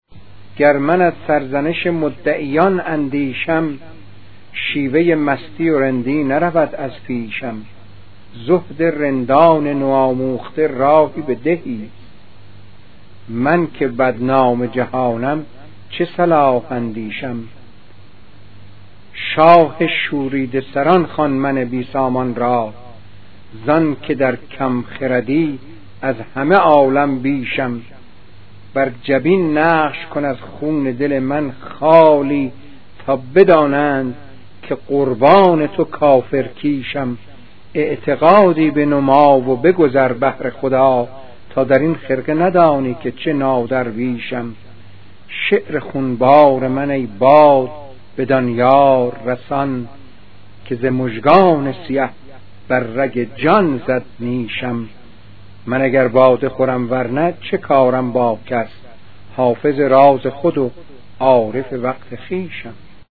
🎵 پخش صوتی غزل با صدای موسوی گرمارودی: